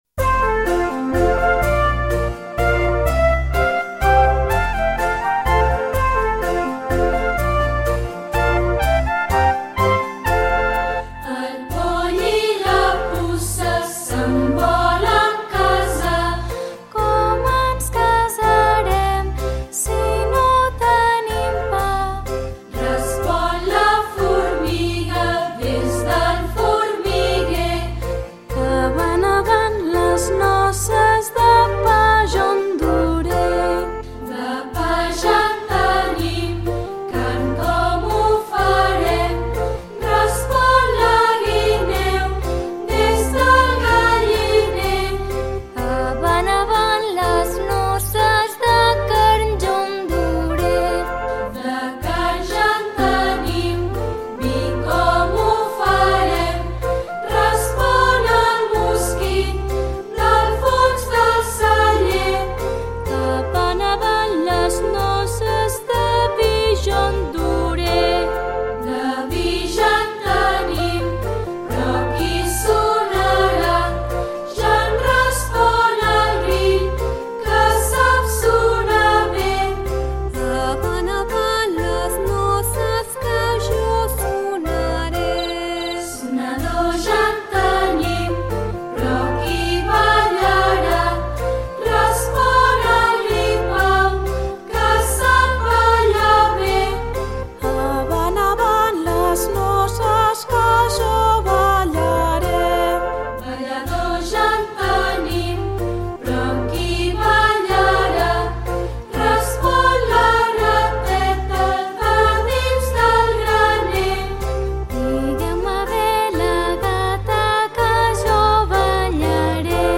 Els nens i nenes de 2n estem cantant la cançó popular catalana El poll i la puça.
Projecte Cantut, cantada per una àvia